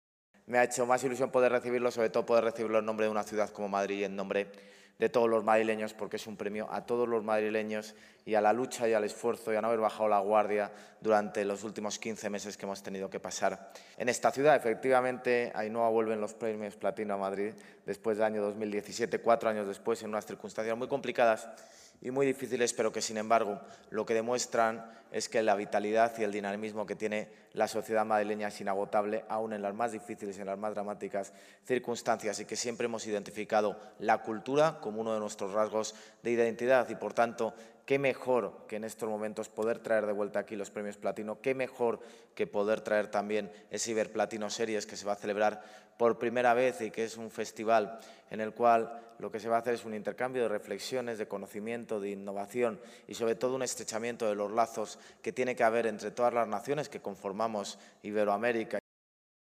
El alcalde de Madrid, José Luis Martínez-Almeida, ha recibido esta mañana el reconocimiento de los Premios Platino del Cine Iberoamericano al Ayuntamiento de Madrid por su apoyo e impulso a la producción audiovisual, durante la presentación de la VIII edición de estos galardones promovidos por EGEDA, Sociedad de Servicios para los Productores Audiovisuales.